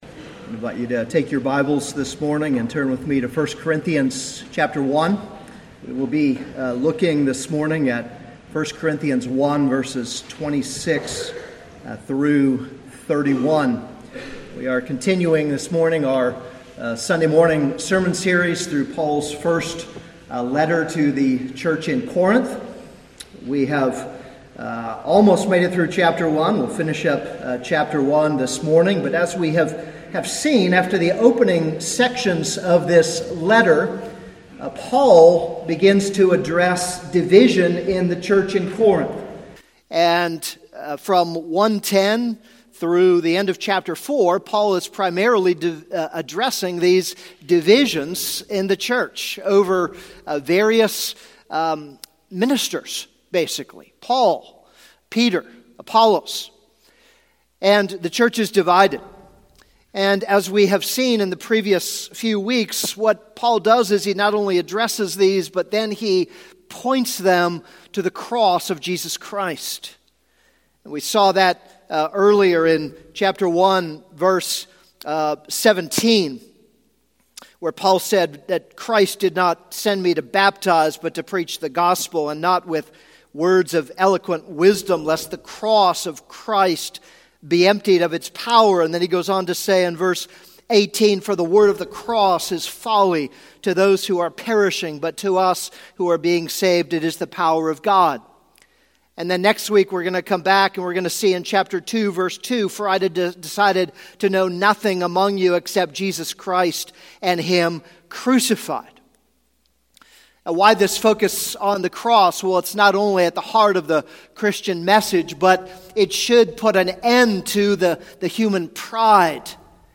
This is a sermon on 1 Corinthians 1:26-31.